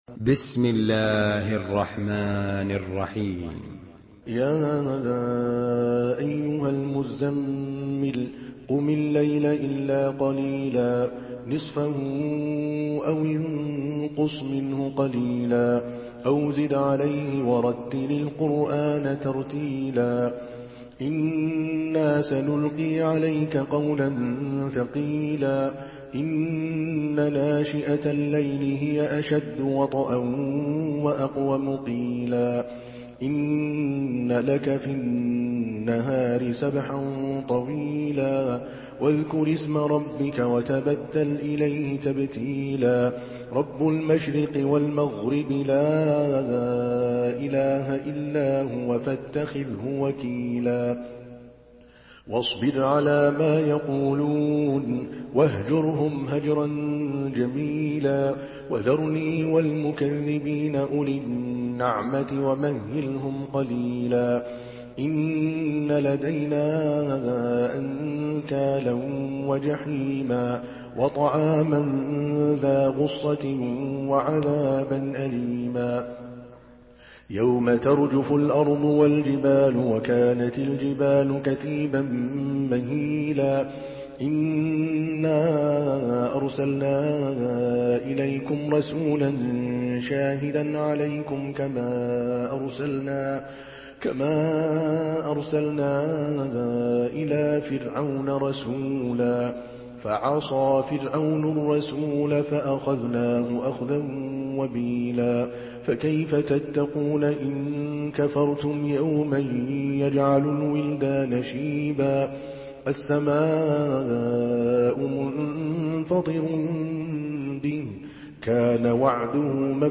Surah Müzemmil MP3 by Adel Al Kalbani in Hafs An Asim narration.
Murattal